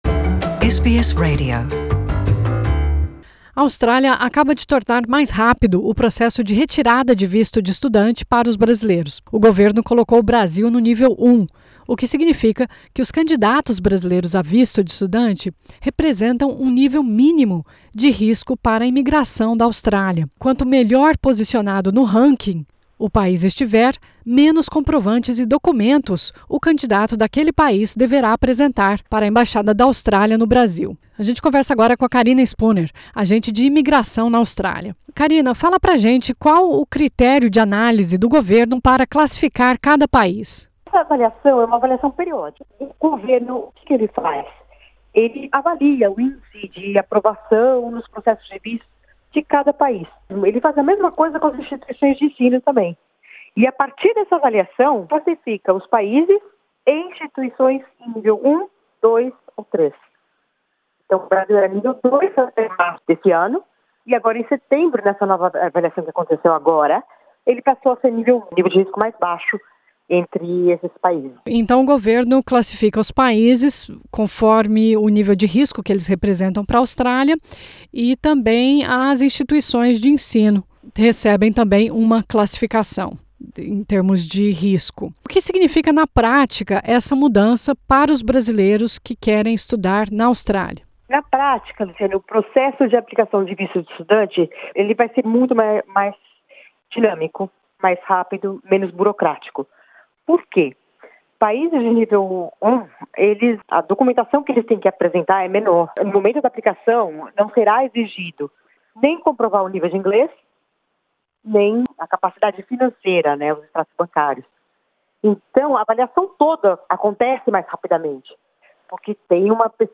Isso significa que candidatos brasileiros precisarão apresentar menos documentos e comprovantes, como extratos bancários e proficiência em inglês, no processo de aplicação de visto. Ouça a entrevista